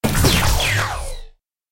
Звуки получения урона
Электронный